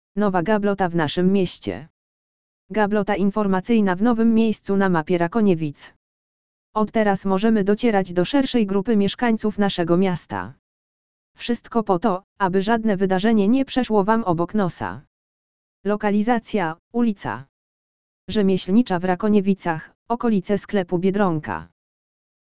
audio_lektor_nowa_gablota_w_naszym_miescie_!.mp3